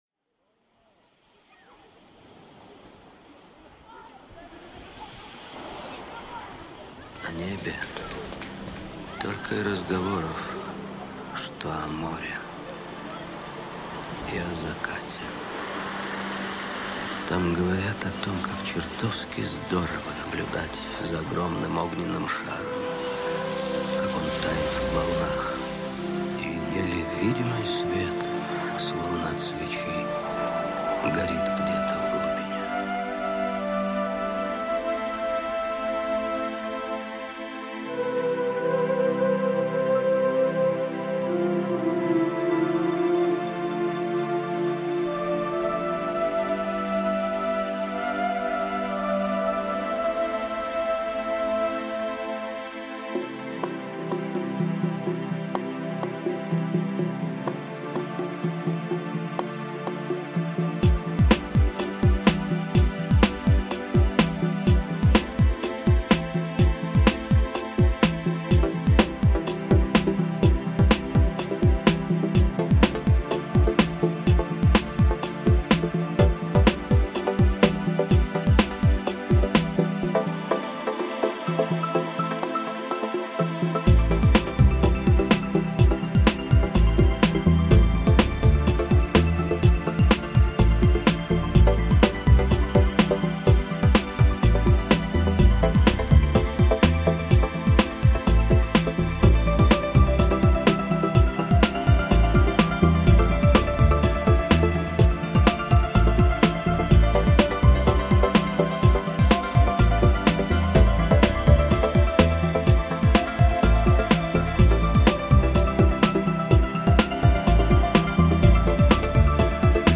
В композиции использована цитата